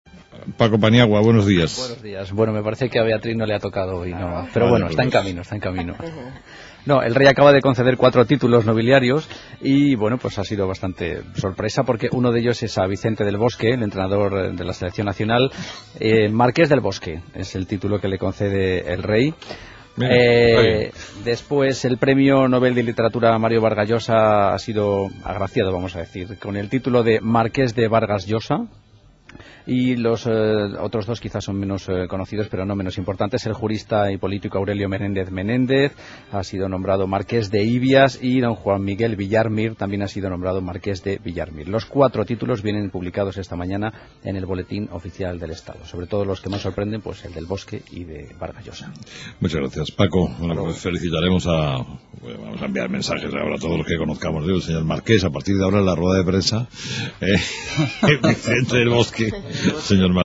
Entrevistado: "Vicente Del Bosque"